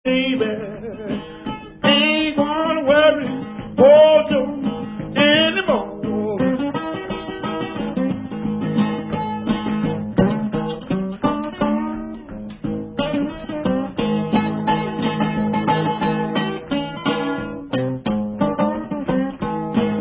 Classic country blues from 1958
sledovat novinky v oddělení Blues